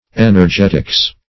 Energetics \En`er*get"ics\, n.